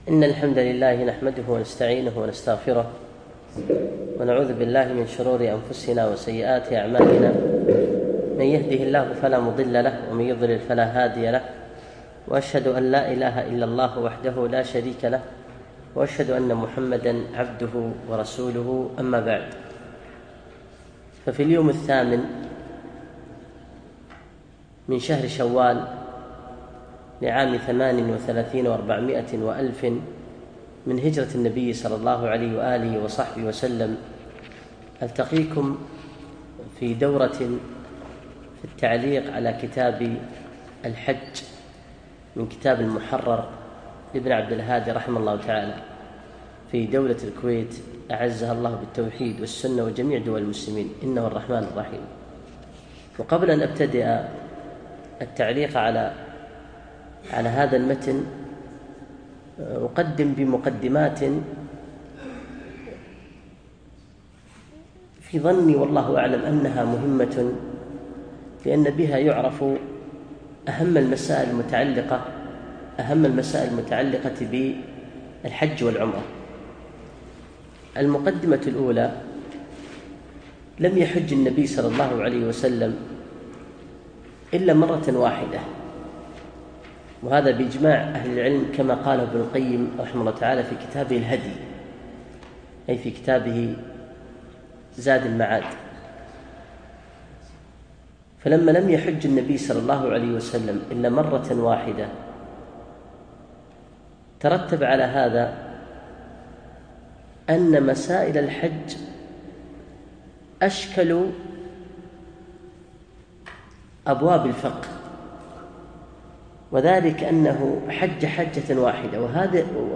يوم الأحد 8 شوال 1438 الموافق 2 7 2017 في مسجد زين العابدين سعد العبدالله
كتاب الحج - الدرس الأول